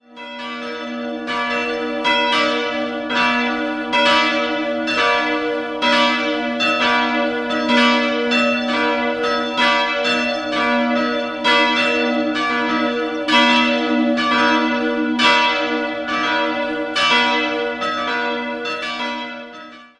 Jahrhundert. 3-stimmiges TeDeum-Geläute: h'-d''-e'' Die große und die kleine Glocke wurden 1950 von Karl Czudnochowsky in Erding gegossen, die mittlere stammt von 1877.